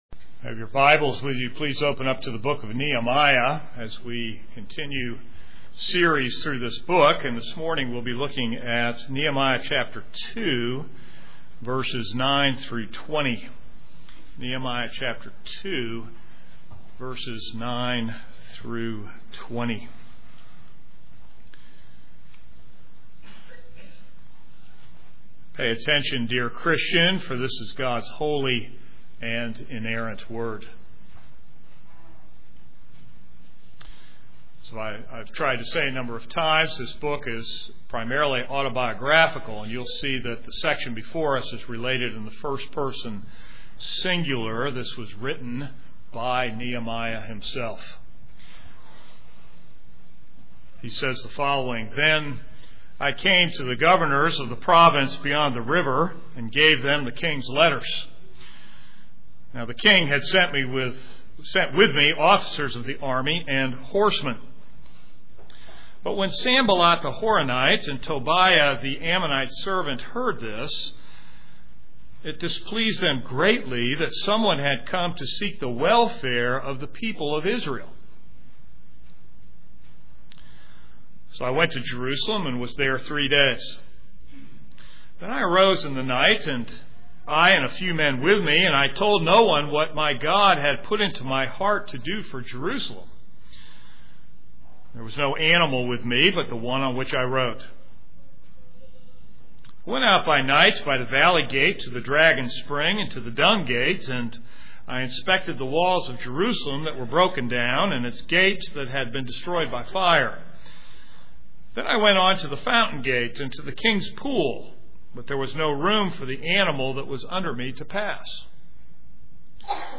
This is a sermon on Nehemiah 2:9-20.